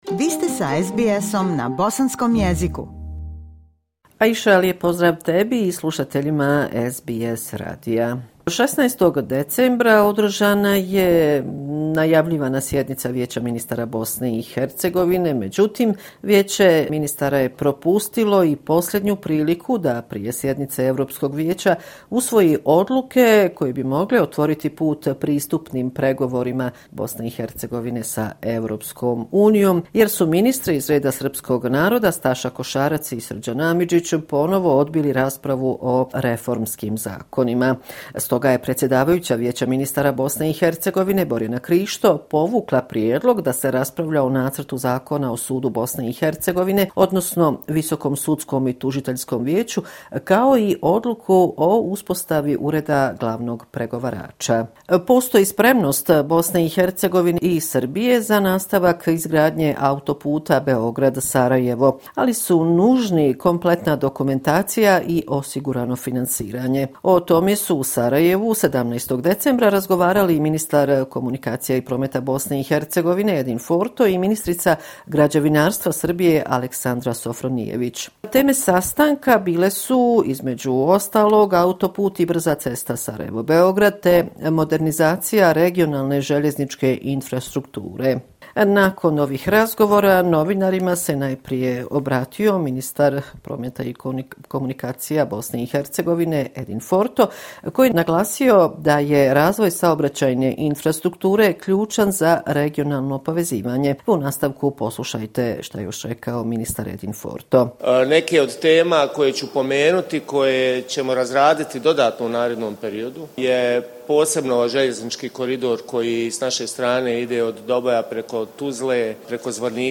Sedmični izvještaj iz Sarajeva